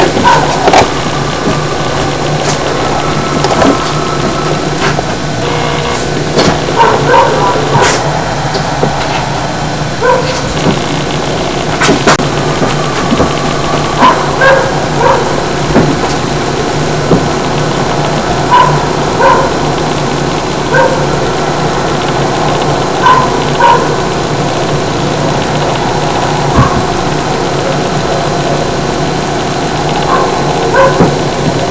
File:Tahoe howl2.wav - Squatchopedia 2.0
Tahoe_howl2.wav